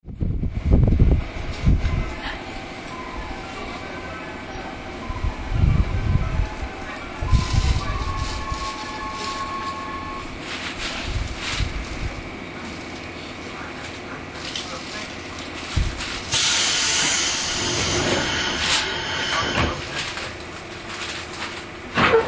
走行音
TK06 211系 早川→小田原 3:10 9/10 上の続きです。